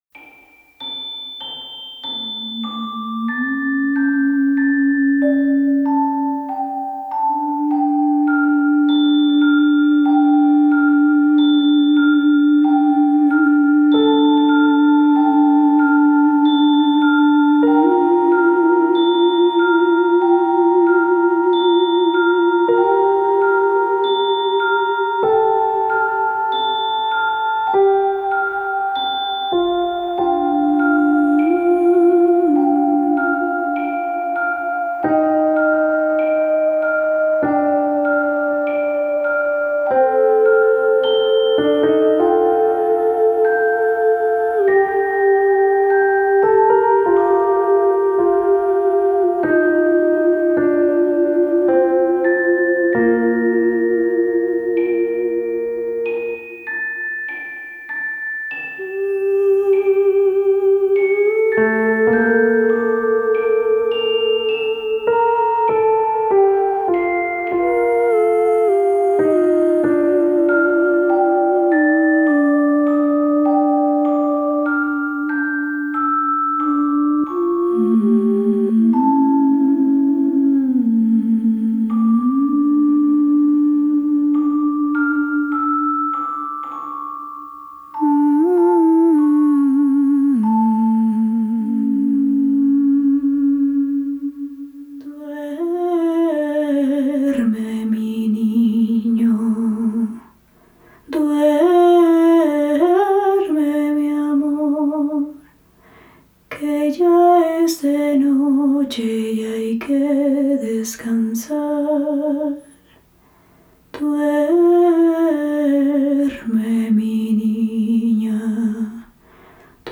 Soprano
Instrumentos sintéticos